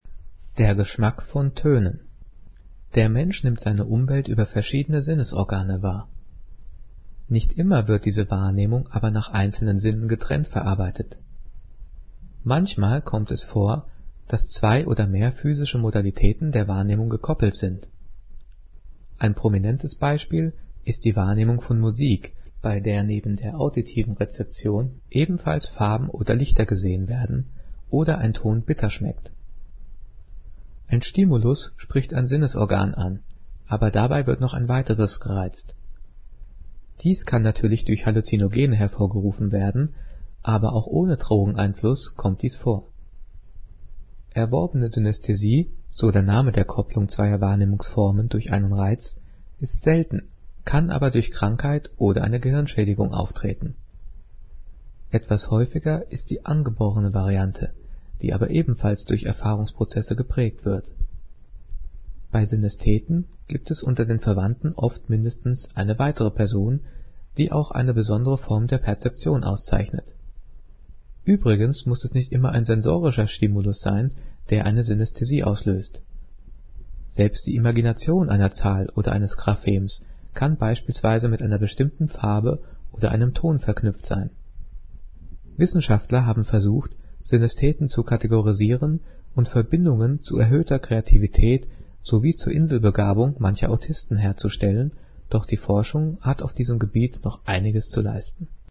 Gelesen:
gelesen-der-geschmack-von-toenen.mp3